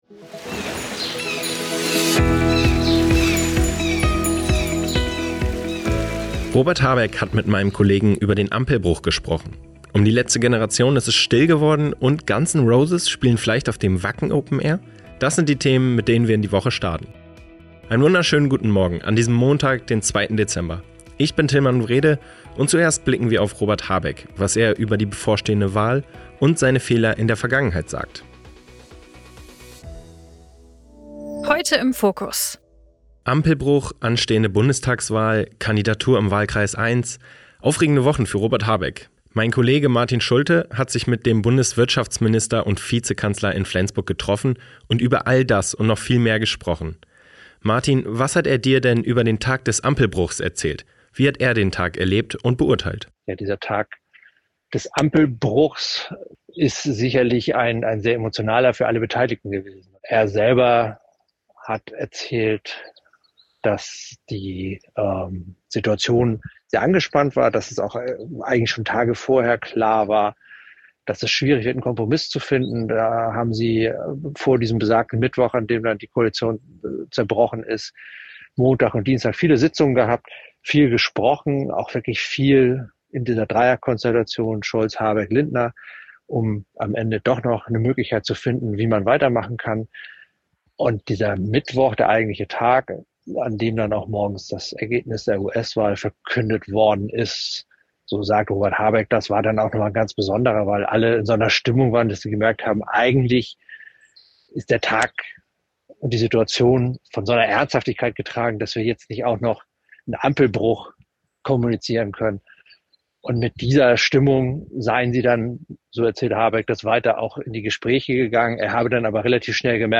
Heute im Fokus: Robert Habeck ist im Interview in seinem
Flensburger Wahlkreisbüro. Er spricht über die bevorstehende Wahl